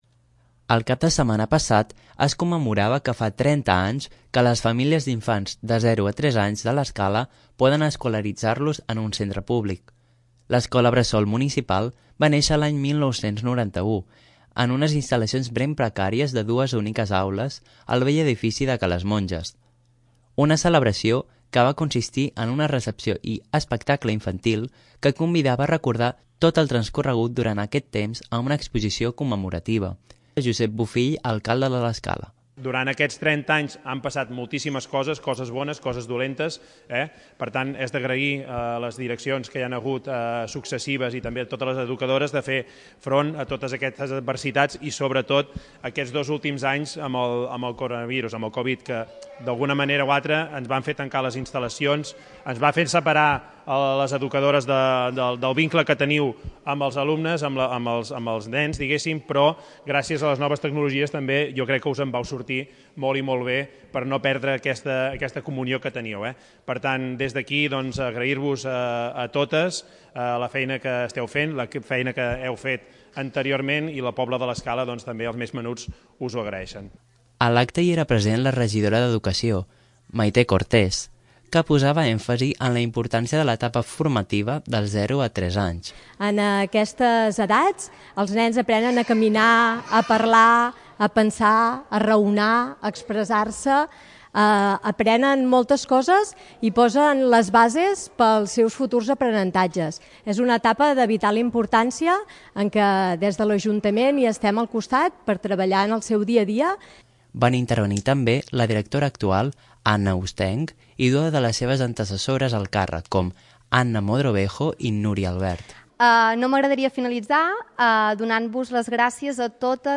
A l'acte hi era present la regidora d'educació, Maite Cortés, que posava èmfasi en la importància de l'etapa formativa dels zero a tres anys.
Entre els assistents hi havia el qui exercia d'alcalde quan va néixer Ballmanetes, Rafel Bruguera, que feia un al·legat a favor de l'ensenyament públic.